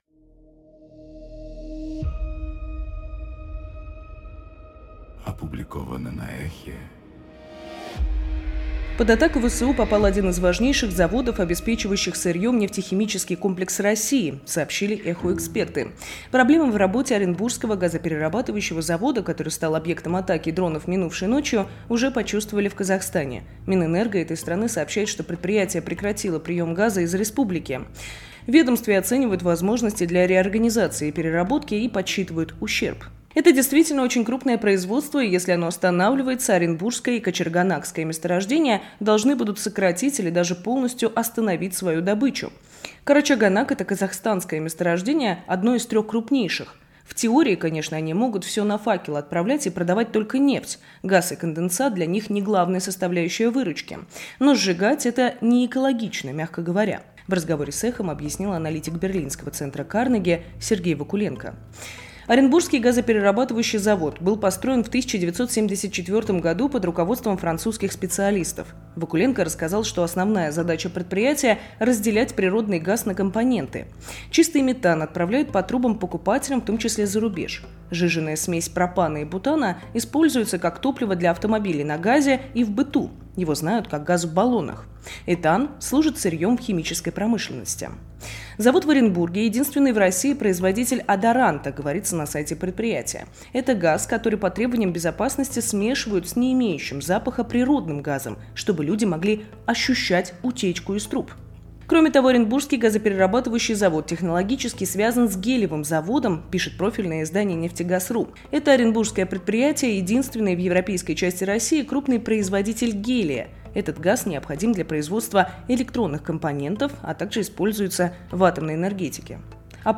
Читает